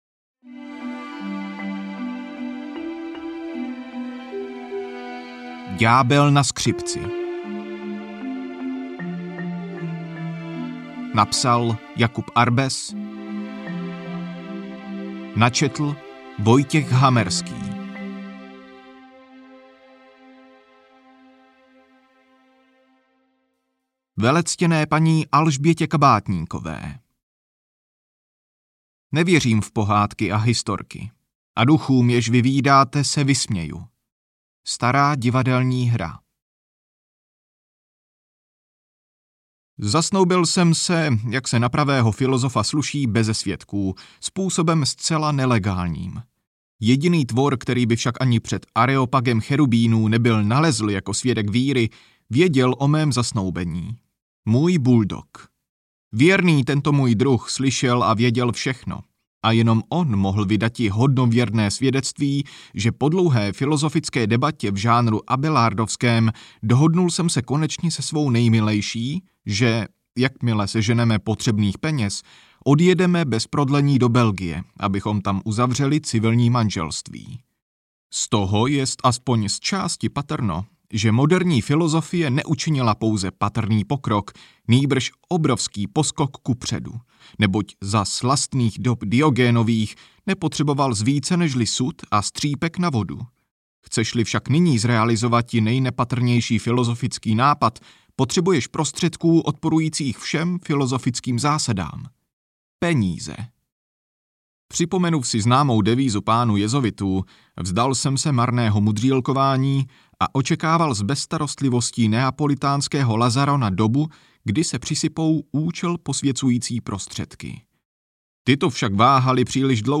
Ďábel na skřipci audiokniha
Ukázka z knihy